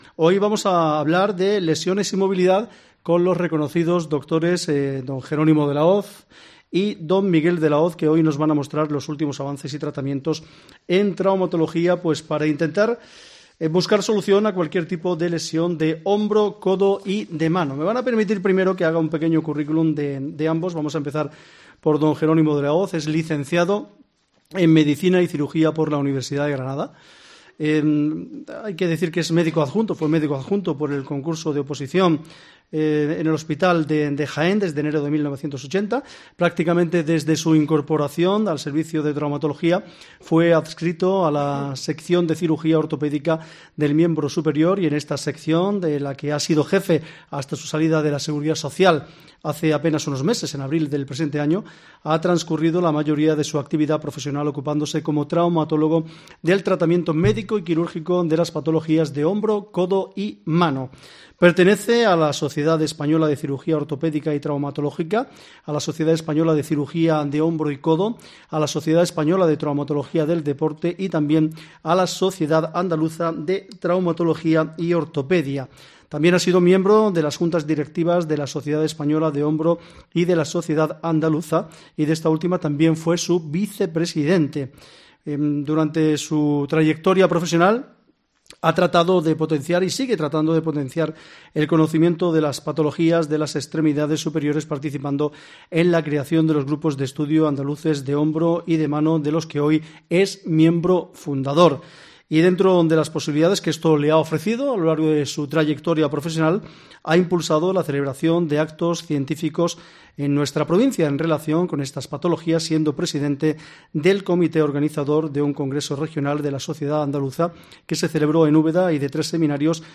Ponencia
FORO SALUD COPE